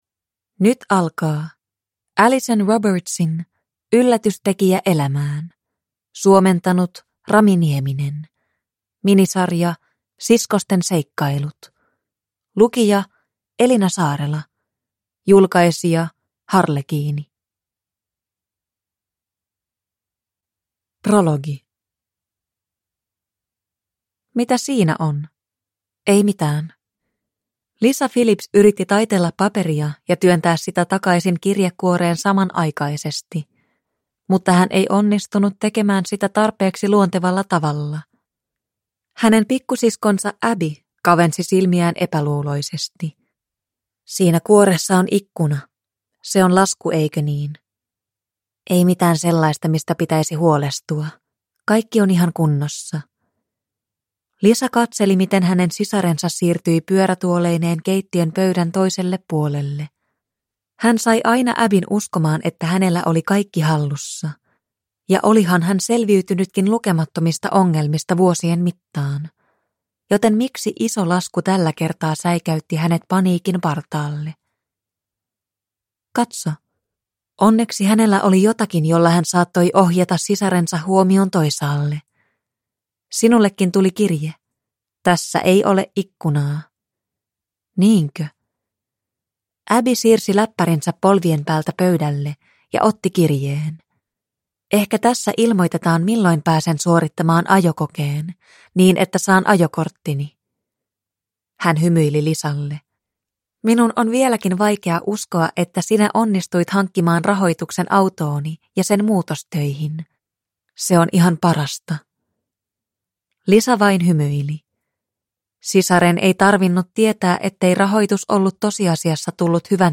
Yllätystekijä elämään – Ljudbok